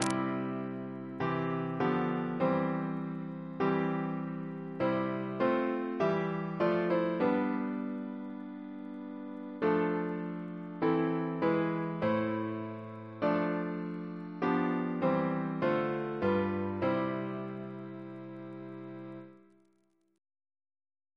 Double chant in C Composer: James Turle (1802-1882), Organist of Westminster Abbey Reference psalters: ACB: 96; RSCM: 139